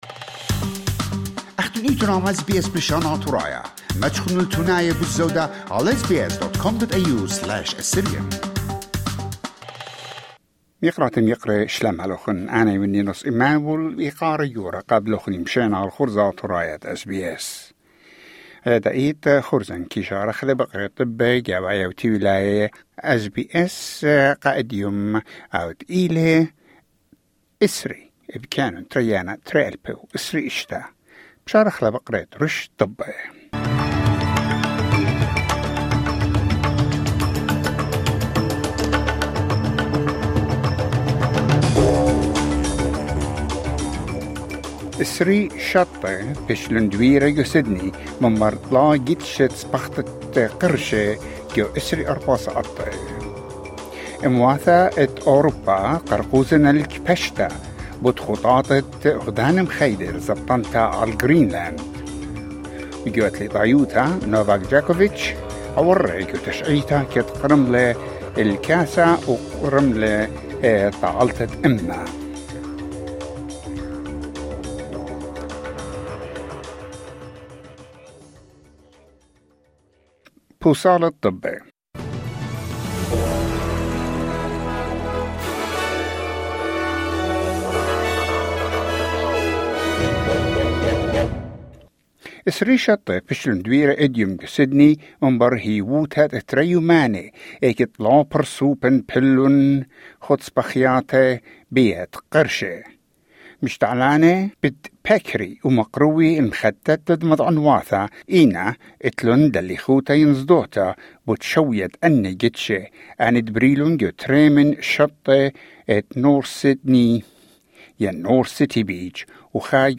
News bulletin 20 January 2026